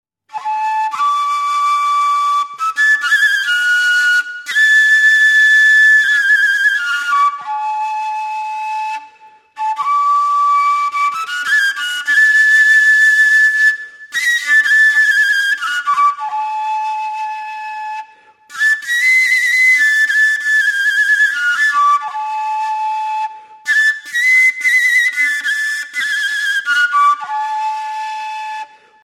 - Traditional songs of the Ukraine
voice, bandura (Ukrainian lute-zither / psaltery), accordion
folk fiddle
tsymbaly = cymbal (dulcimer)
double bass